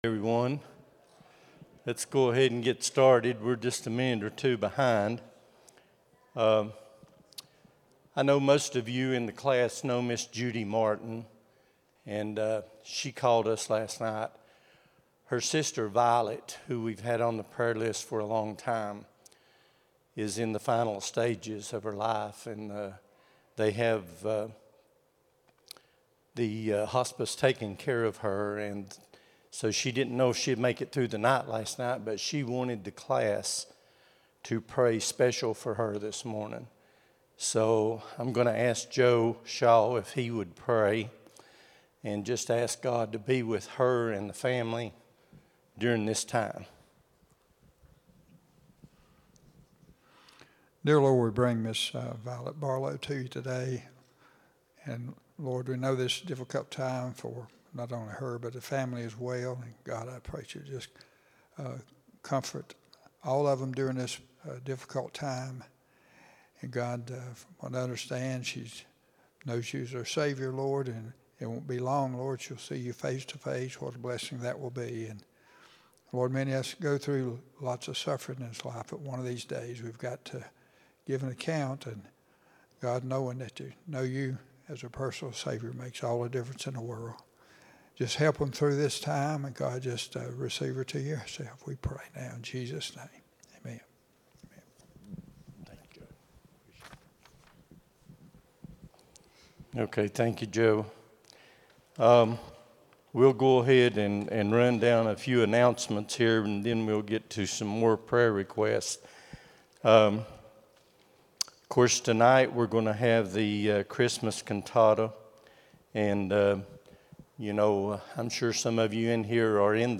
12-14-25 Sunday School | Buffalo Ridge Baptist Church